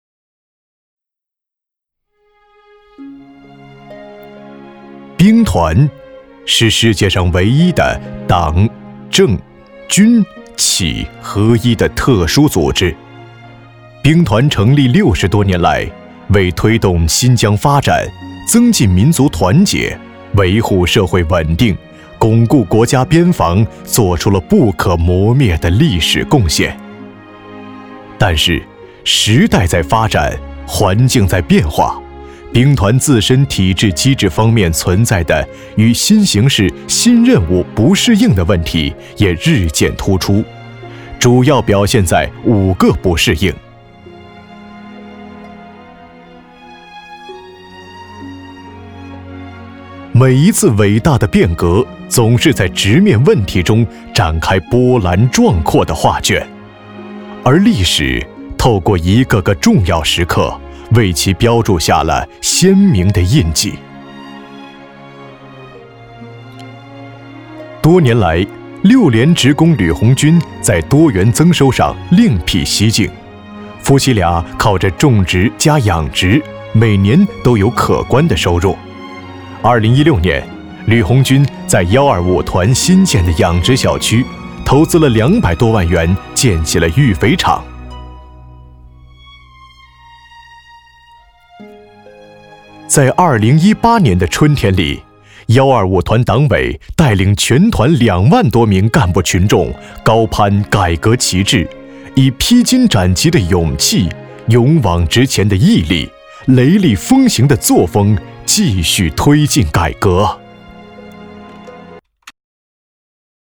3 男国273_专题_政府_兵团解说党政类_舒缓 男国273
男国273_专题_政府_兵团解说党政类_舒缓.mp3